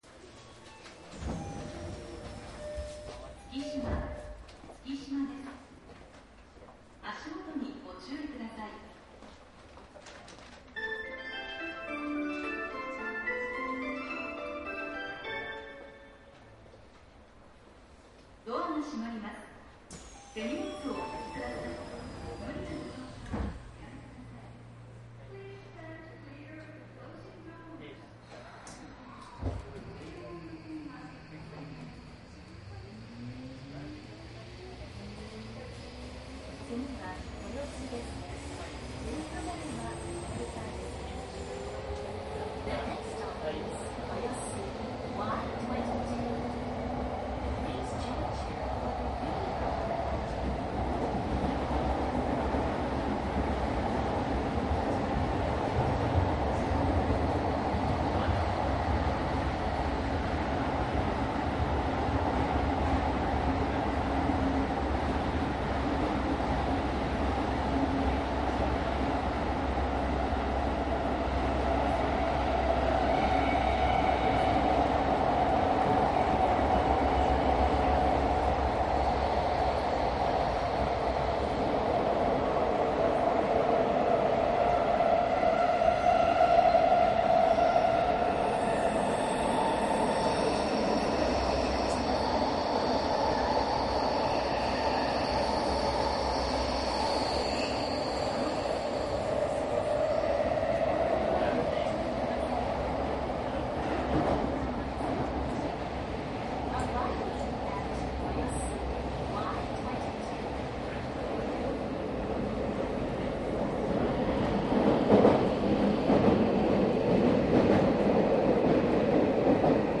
内容は東京メトロ有楽町線17000系（１０両編成）    走行音 CD
走行音はありふれた最近の東京メトロの標準で量産型のPMSMです。東池袋～要町（和光市方面）で客の声が聞こえます。
感染症対策で窓が開いている場合があります。
全線でホームドアが設置されたので駅メロがかなりうるさく感じます。
※7000系の引退で代替で用意された１０両編成の車両で録音。
マスター音源はデジタル44.1kHz16ビット（マイクＥＣＭ959）で、これを編集ソフトでＣＤに焼いたものです。